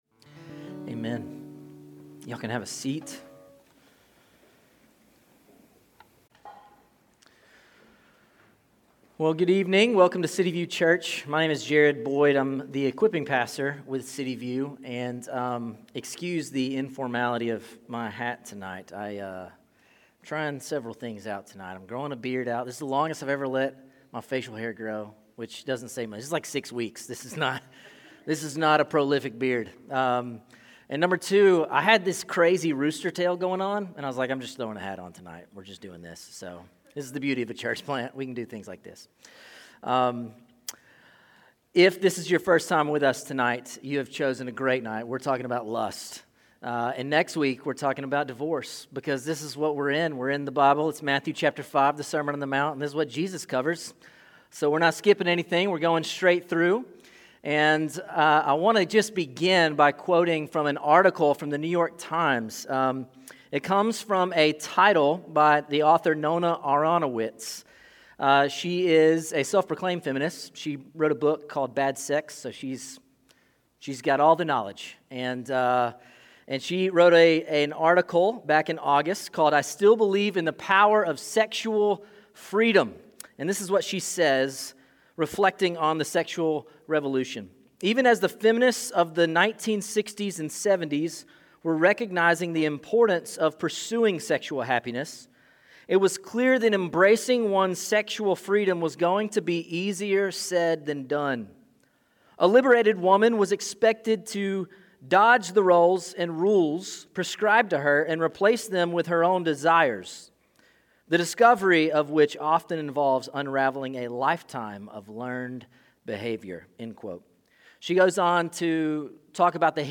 City View Church - Sermons "Lust Enslaves.